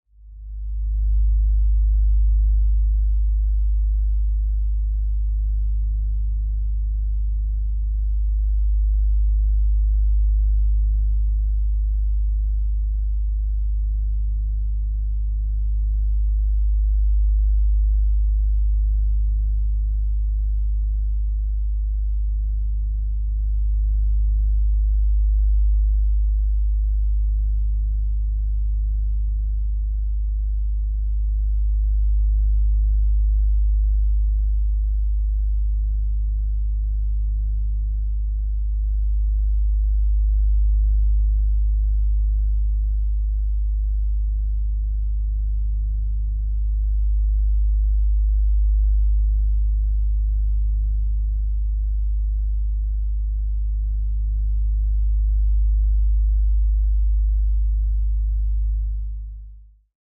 In the spirit of the attempt, here is my version of the hum that I hear.
There is a basic generator called a "Test Oscillator" and it generates a sine wave between 20Hz-20kHz.
So, I generated a second frequency at 51 kHz which generated the rumble.
Careful with the volume at the start.
Attachments 51, 78 & 102 kHz frequencies sum (The Hum).mp3 51, 78 & 102 kHz frequencies sum (The Hum).mp3 707.8 KB